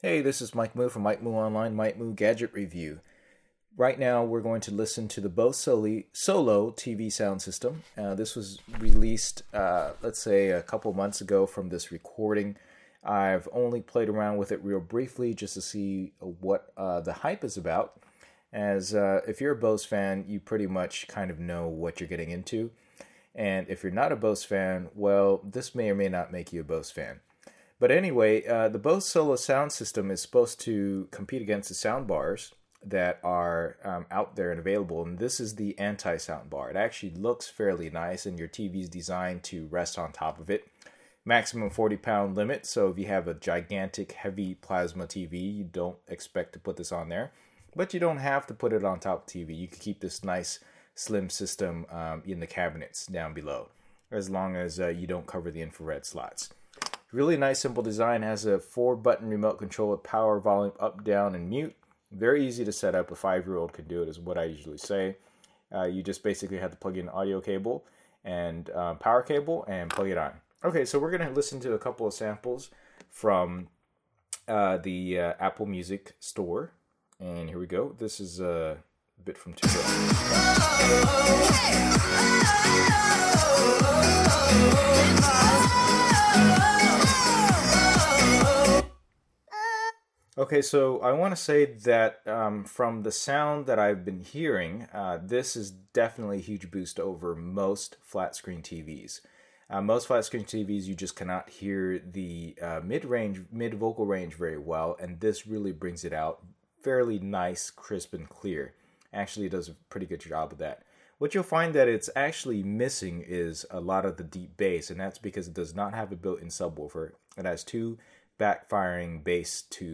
Quick overview and review of the Bose Solo TV Sound System with audio sound samples streamed directly from iTunes Music from an iPhone 5S using analog input.